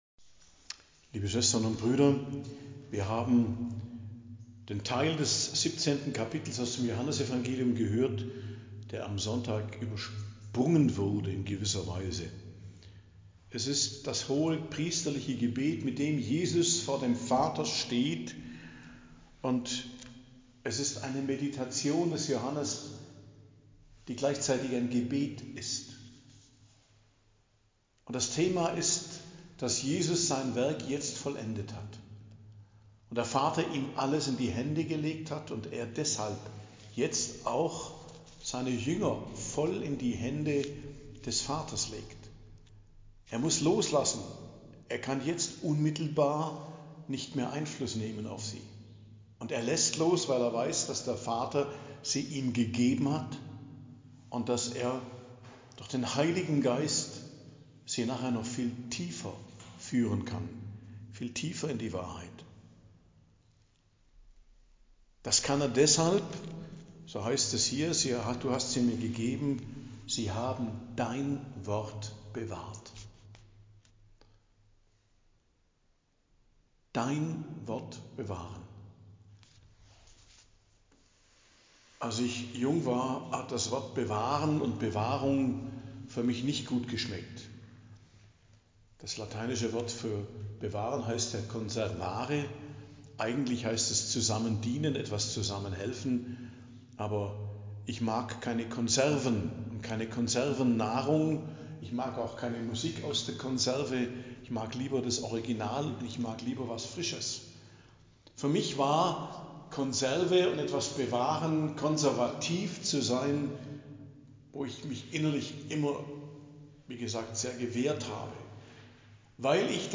Predigt am Dienstag der 7. Osterwoche, 3.06.2025